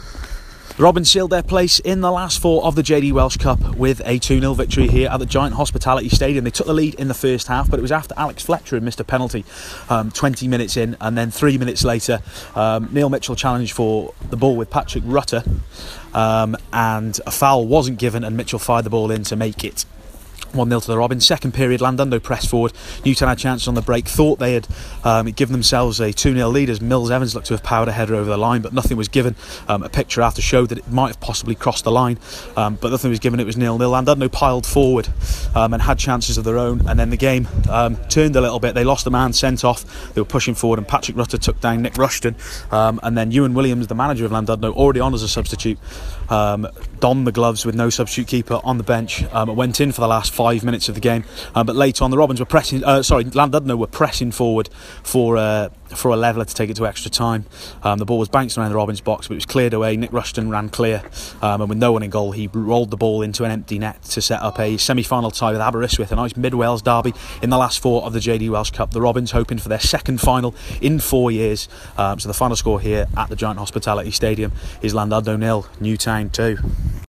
AUDIO REPORT - JD Welsh Cup Llandudno 0-2 Robins